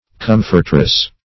Meaning of comfortress. comfortress synonyms, pronunciation, spelling and more from Free Dictionary.
Search Result for " comfortress" : The Collaborative International Dictionary of English v.0.48: Comfortress \Com"fort*ress\, n. A woman who comforts.